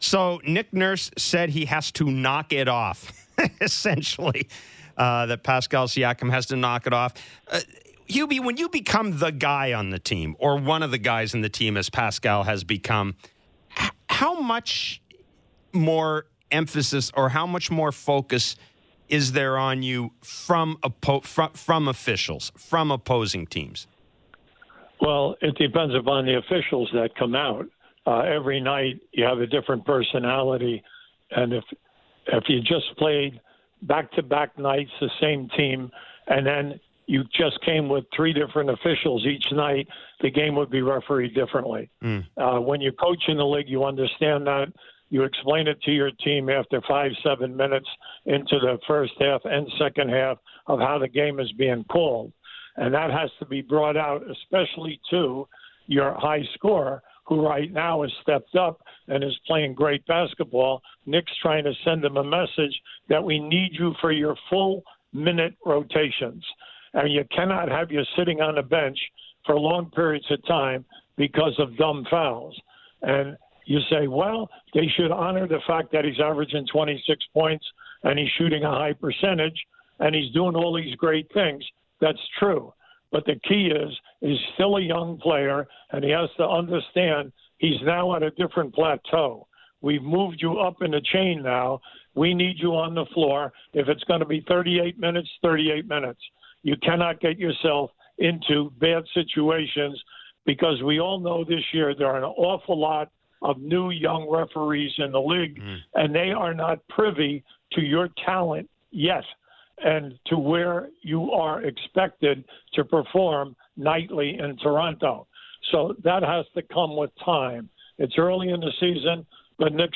Share Download You can listen to Brown’s entire appearance on Writers Bloc in the player above where, among other topics, he also discussed Pascal Siakam’s apparent foul-trouble problem.